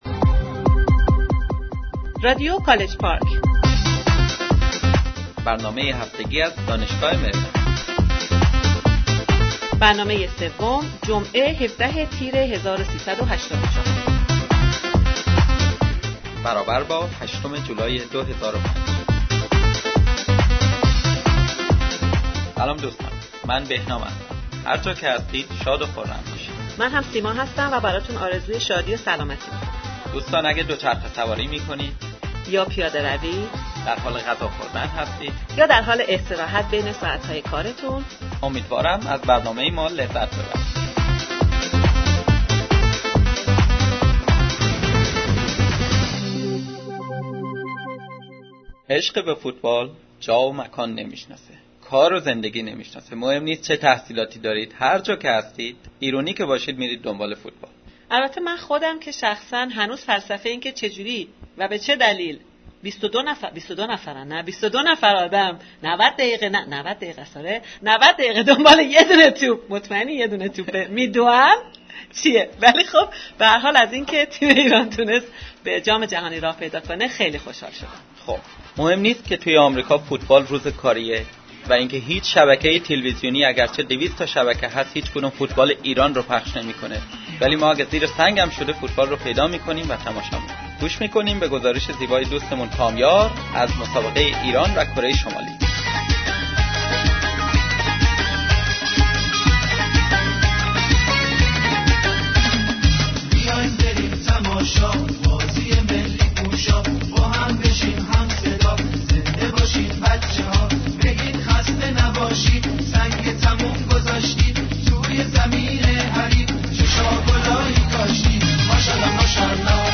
مصاحبه با مرضيه برومند